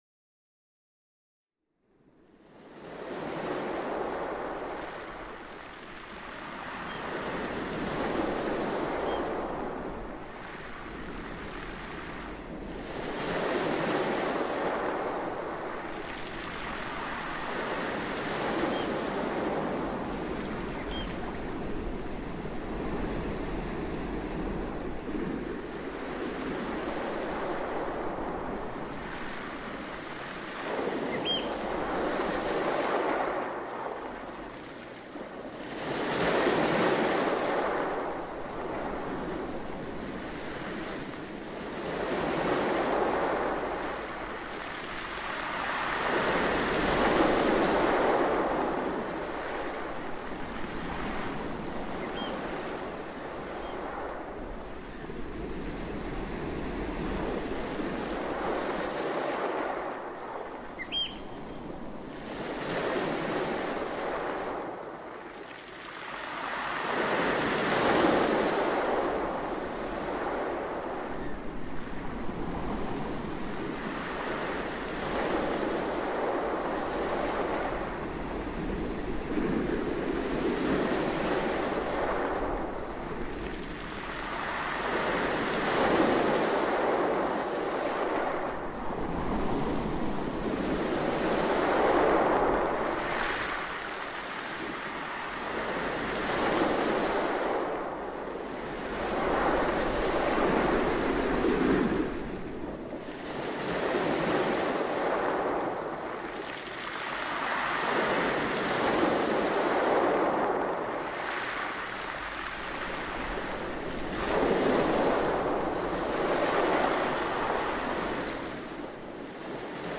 Ocean-Waves-Nature-Sounds.mp3